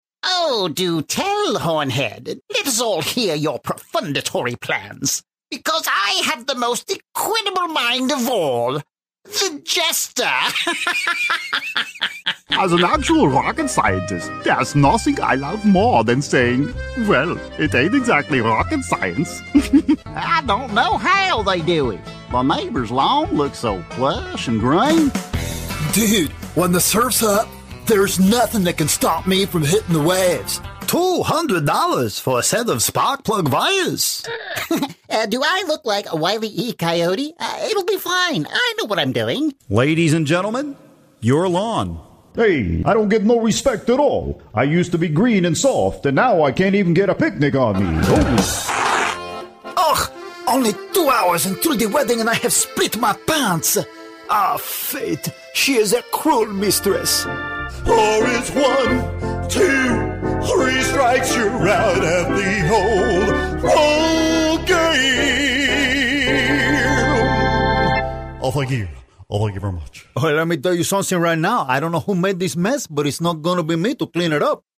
A versatile Voice Actor who takes direction well and can also deliver a variety of unique character voices.
Conversational, guy next door, humorous, enthusiastic, character, youthful, caring.
Sprechprobe: Sonstiges (Muttersprache):